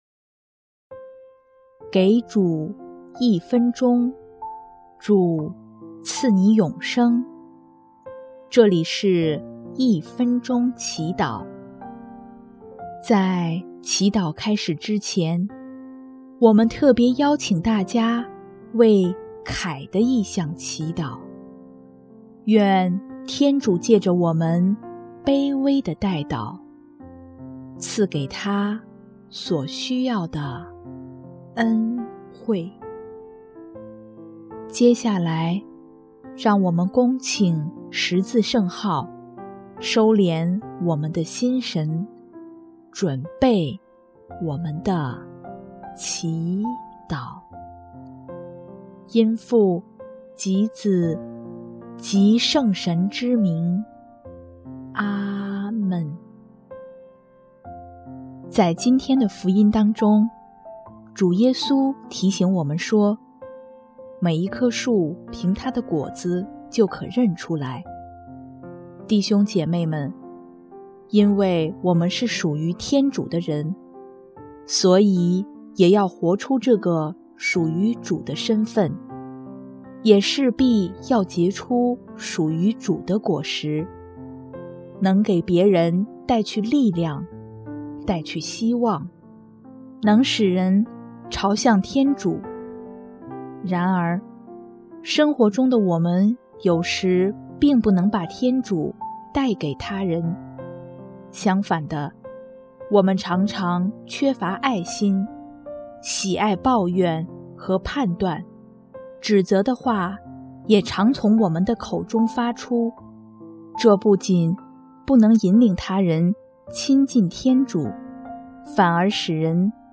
【一分钟祈祷】|9月16日 行善行，结善果